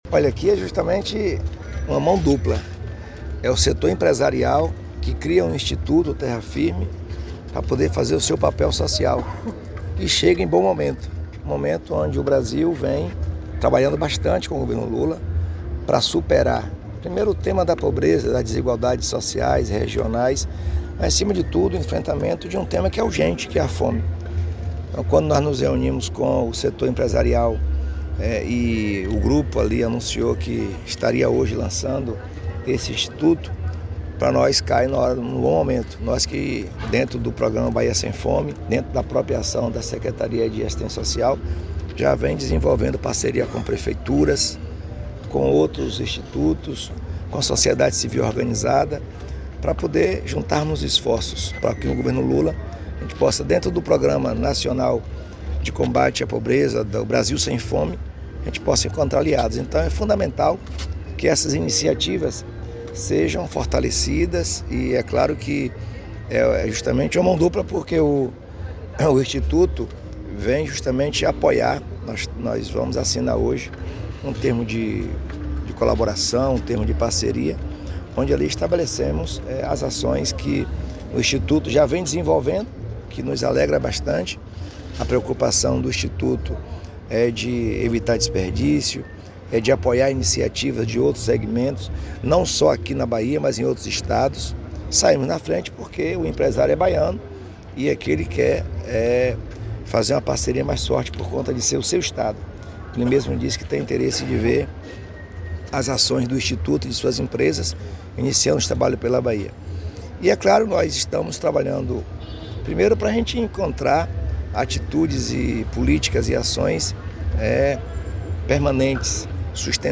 🎙 Jerônimo Rodrigues – Governador da Bahia